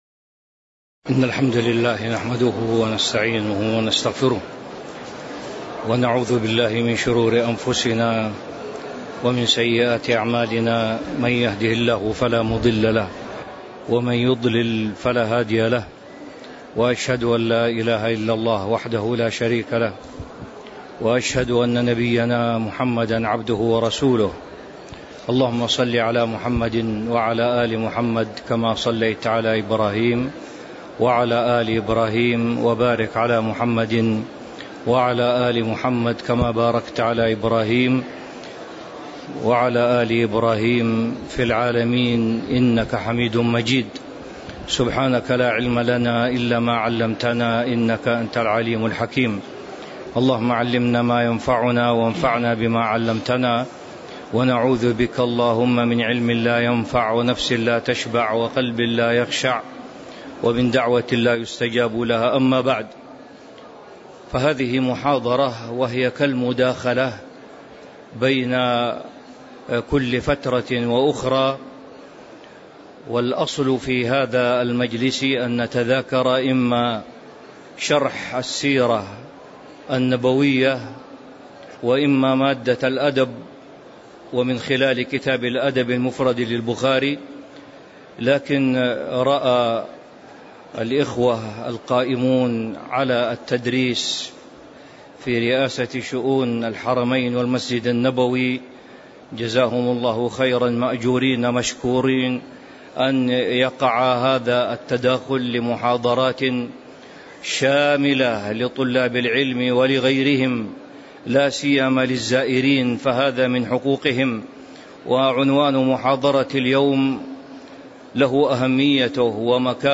تاريخ النشر ٢٥ رجب ١٤٤٤ هـ المكان: المسجد النبوي الشيخ